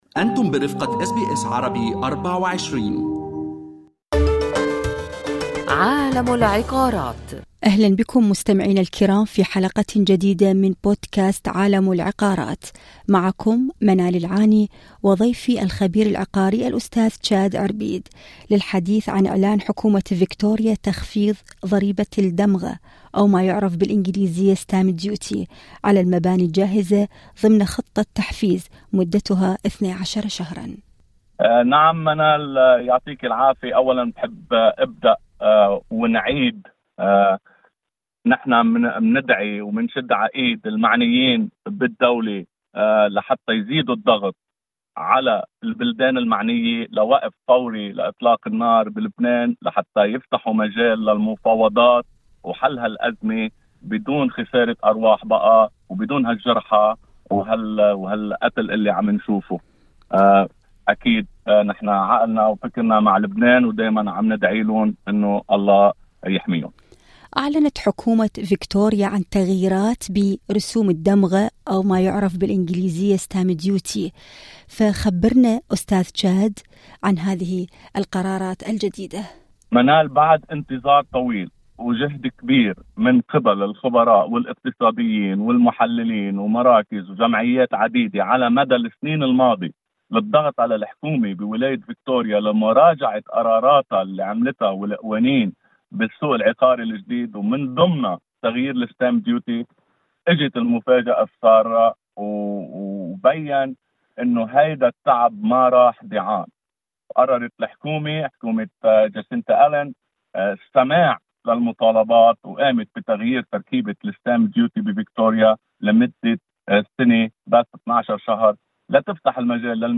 فكيف يؤثر قرار حكومة فيكتوريا على سوق الإيجار والبيع والشراء على حد سواء؟ المزيد في المقابلة الصوتية اعلاه